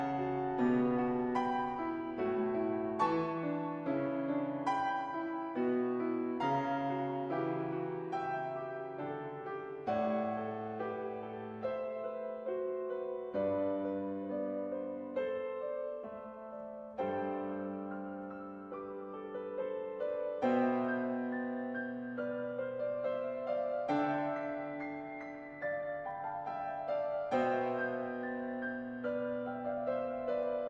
genre: Classical